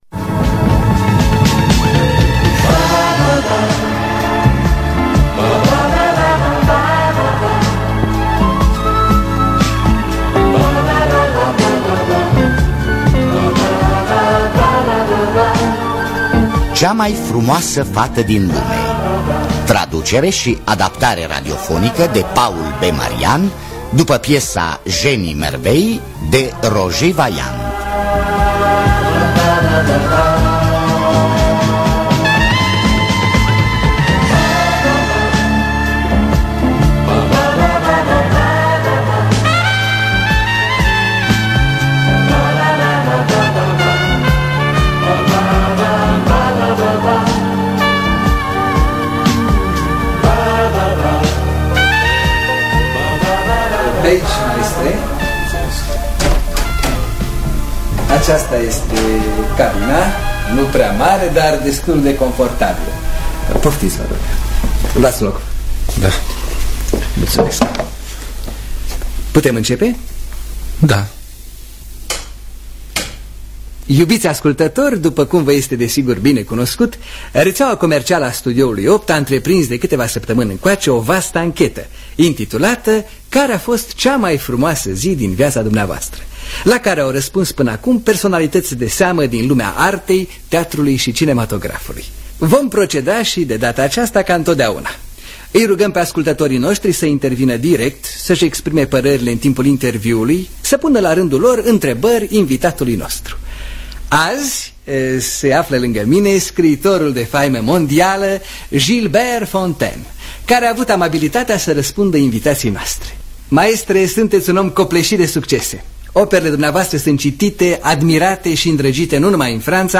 Roger Vailland – Cea Mai Frumoasa Fata Din Lume (1982) – Teatru Radiofonic Online